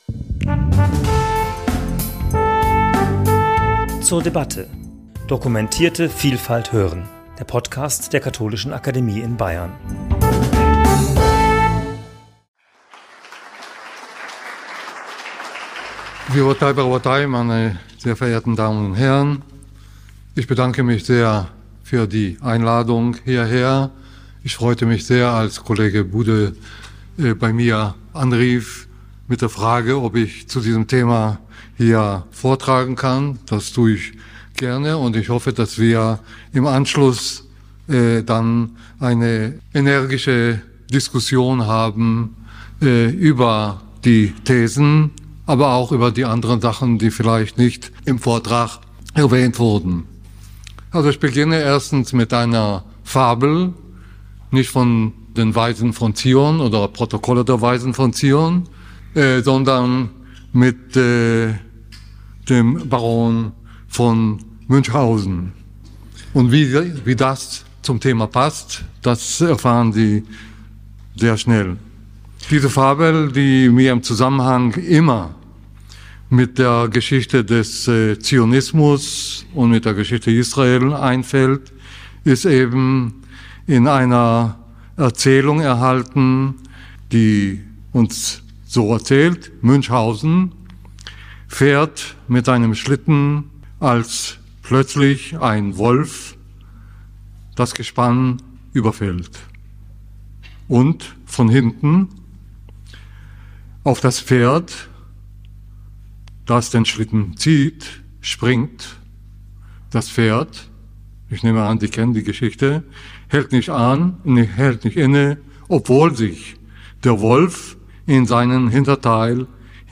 Prof. Dr. Moshe Zimmermann referierte zum Thema 'Religiöse Strömungen in der israelischen Gesellschaft' am 24.6.2024 in der Katholischen Akademie in Bayern.